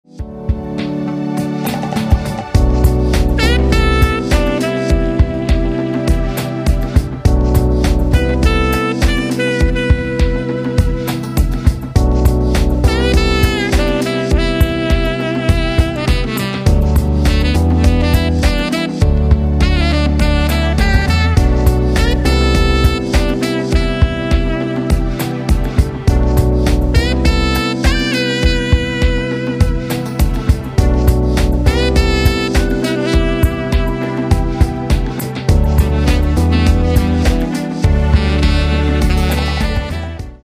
Tenor-Saxophon